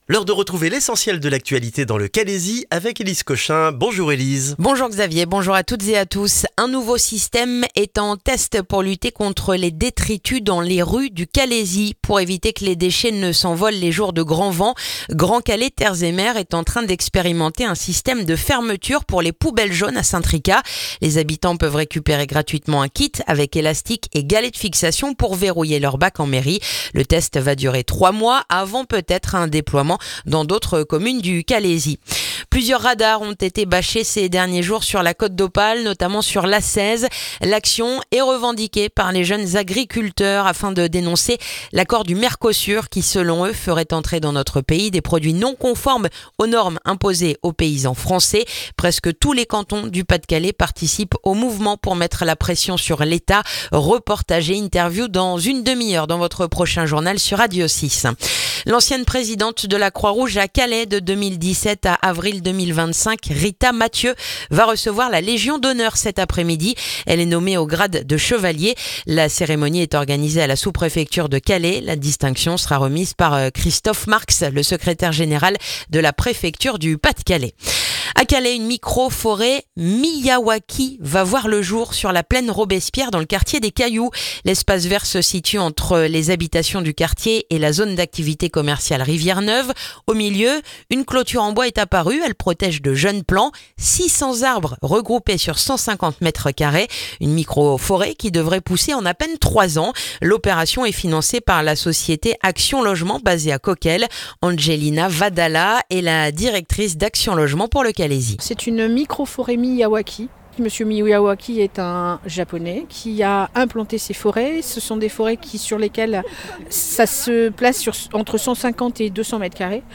Le journal du jeudi 11 décembre dans le calaisis